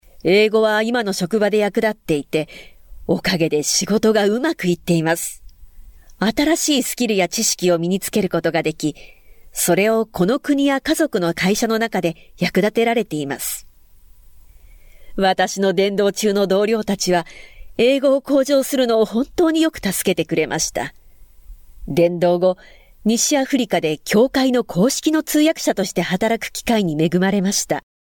Female Professional Voice Over Talent | VoicesNow Voiceover Actors